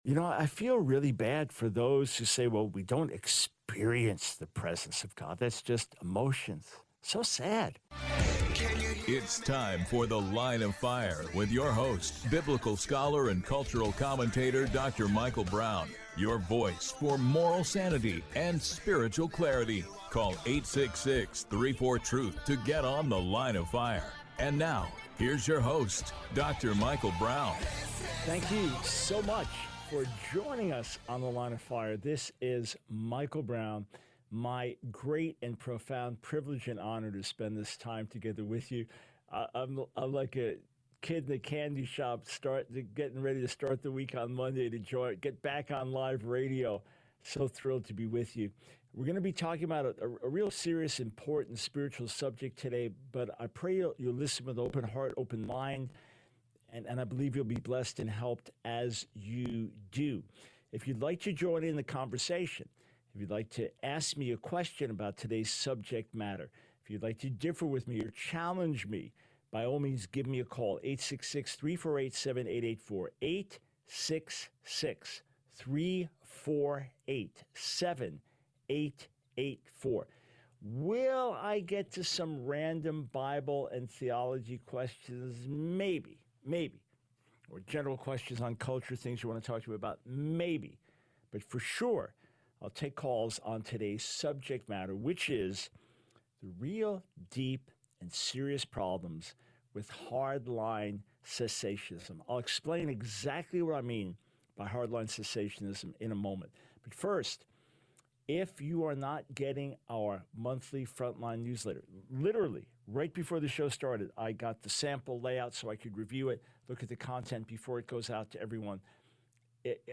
The Line of Fire Radio Broadcast for 08/12/24.